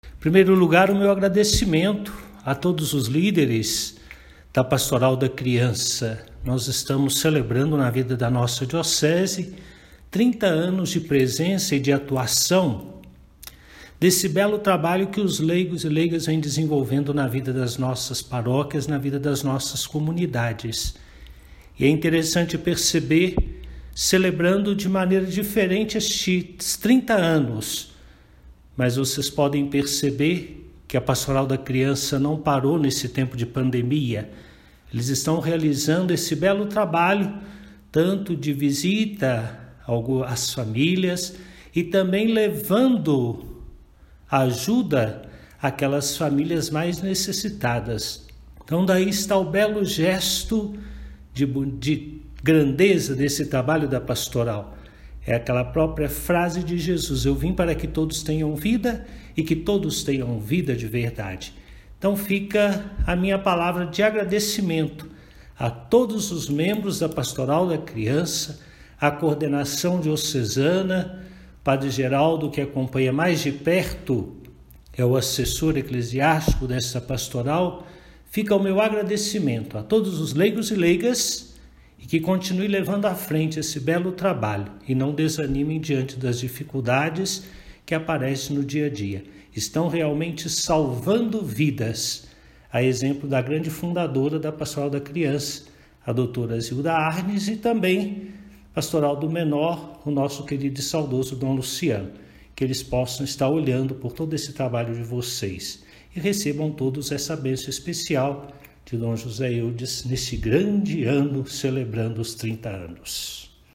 Para celebrar a data, o bispo diocesano, Dom José Eudes Campos do Nascimento, agradeceu os integrantes da Pastoral e falou sobre o importante trabalho realizado na comunidade, inclusive, neste período de pandemia.
Dom-José-Eudes-falar-sobre-os-30-anos-da-Pastoral-da-Criança.mp3